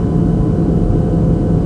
hovercraft.mp3